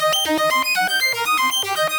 SaS_Arp03_120-C.wav